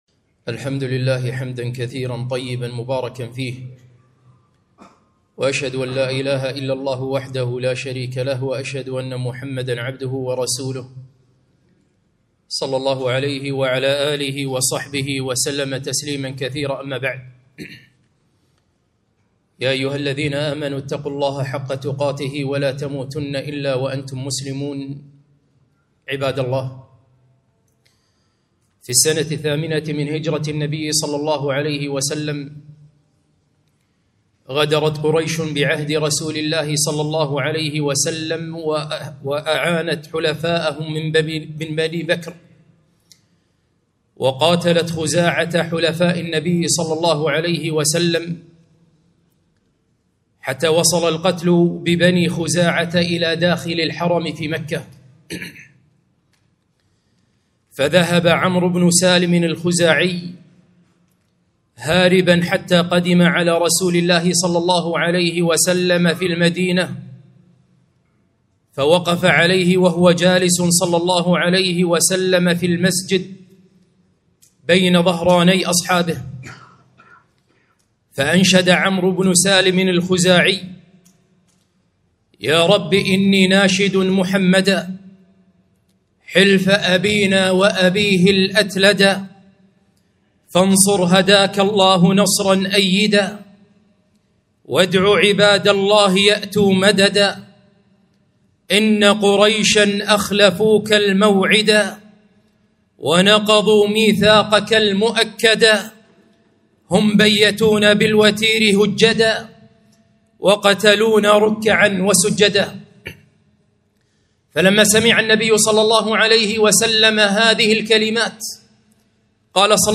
خطبة - قصور الكويت